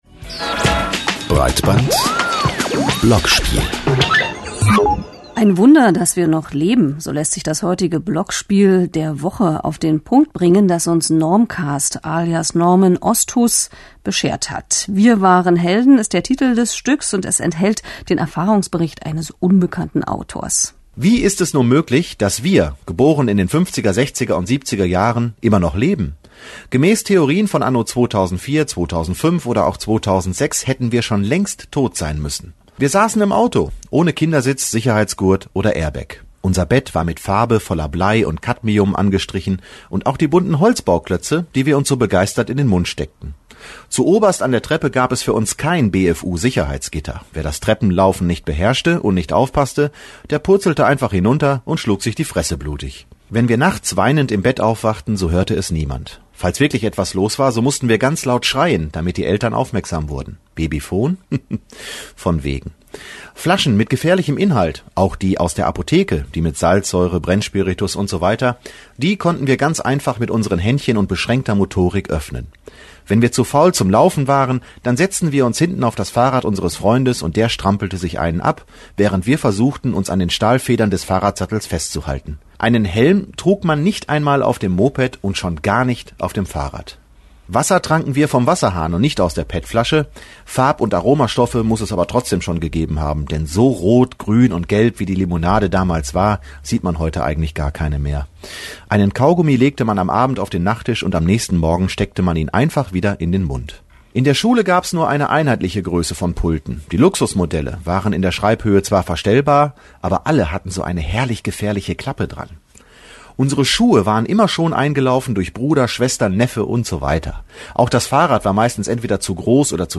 Am heutigen Samstagnachmittag wurde das Werk in der Sendung "Breitband" auf den Frequenzen vom "Deutschlandradio Kultur" gespielt. Zwar in gekuerzter Form, aber immerhin mit einem kleinen telefonischen Gespraech, welches die Redaktion bereits gestern mit mir aufgezeichnet hatte.